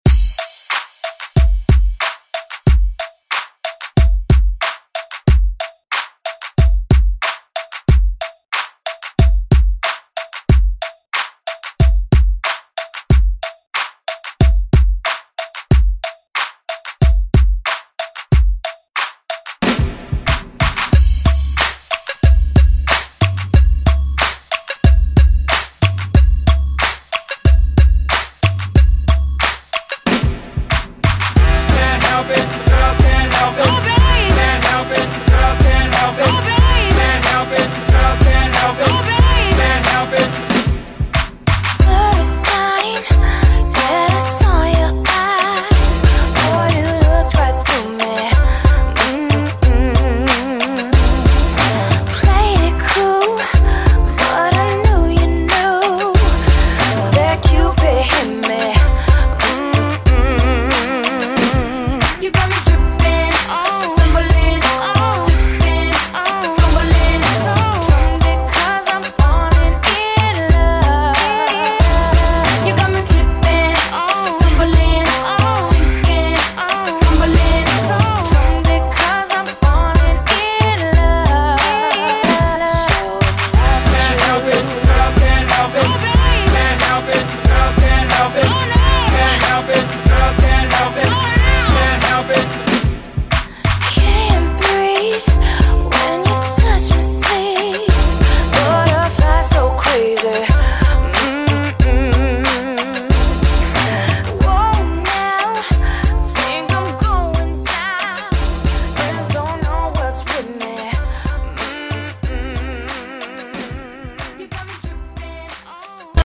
Genres: 60's , RE-DRUM , ROCK Version: Clean BPM: 109 Time